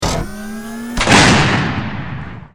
battlesuit_pulsegun.wav